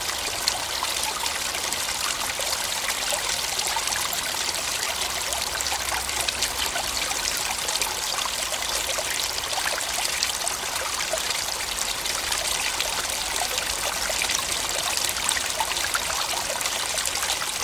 Creek_Water_Flowing_Splashing.ogg